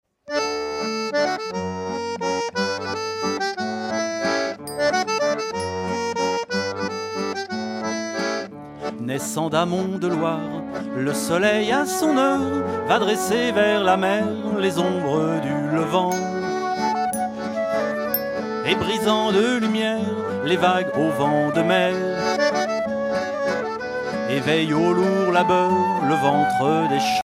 danse : valse
Concert donné en 2004
Pièce musicale inédite